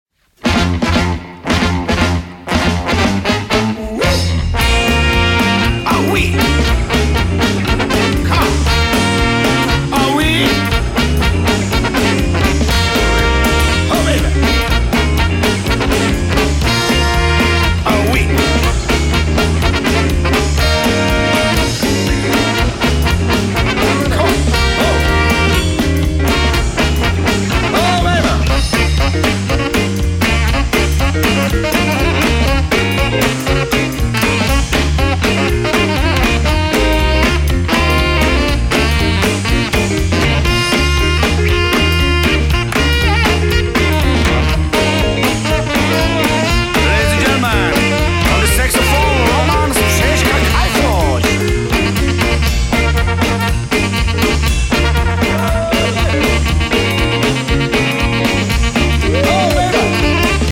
hosty na dechové nástroje.